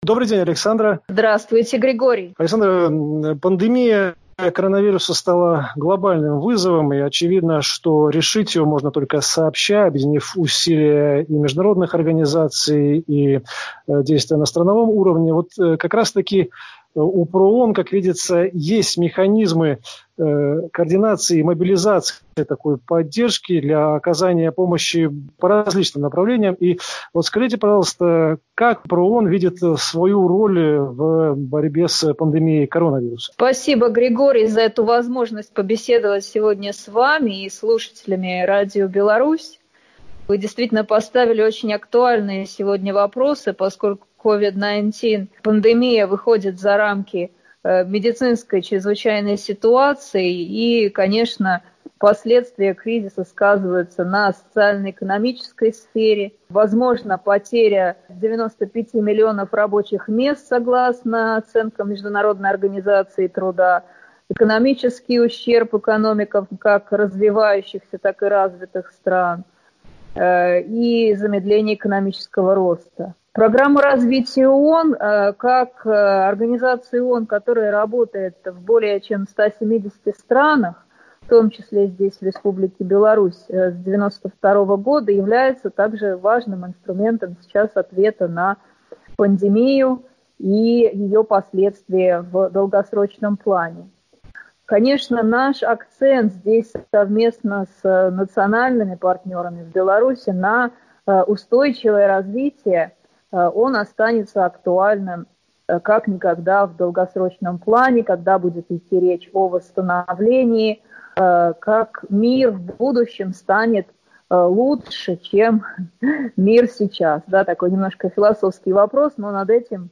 интервью) | Радио Беларусь